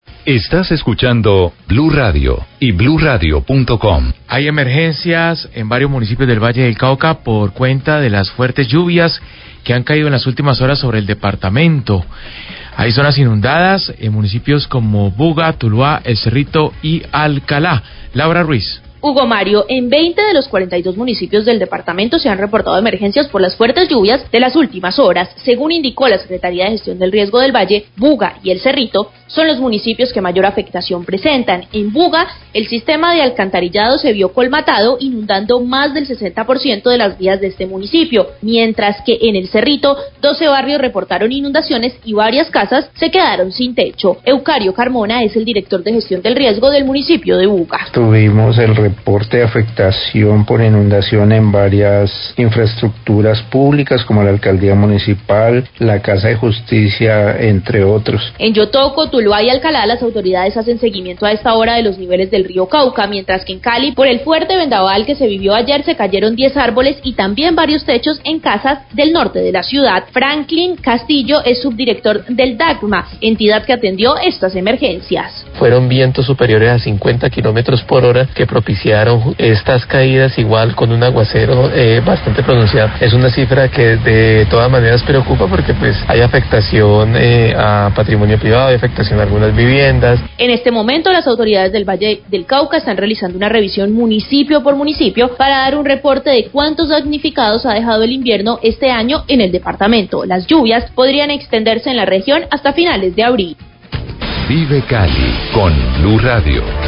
Radio
Habla Eucario Carmona, director de la Oficina de Gestión del Riesgo de Buga, hace un balance de las afectaciones.